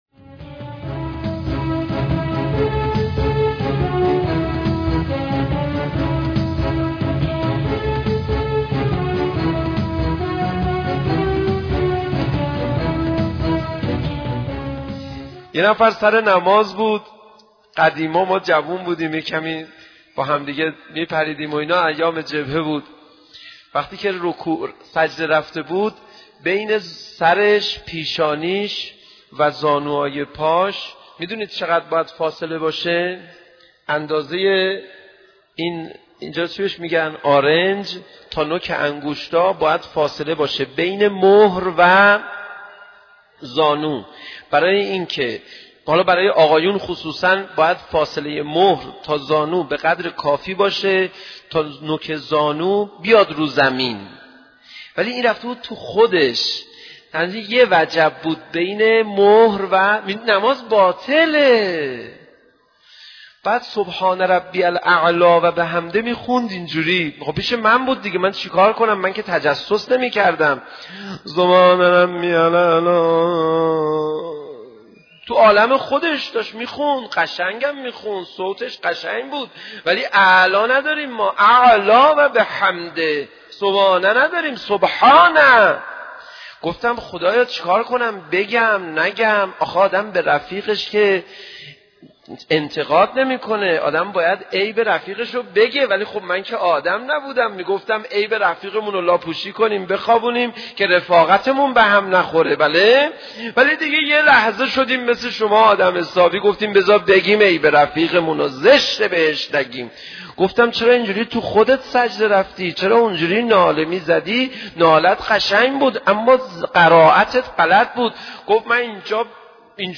سخنرانی حجت الاسلام پناهیان در مورد نماز